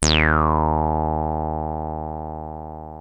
303 D#2 4.wav